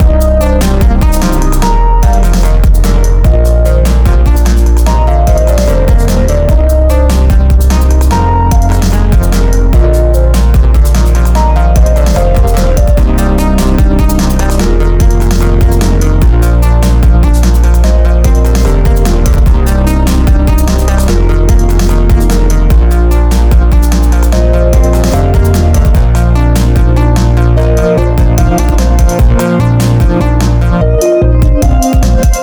Electro-Pumping.mp3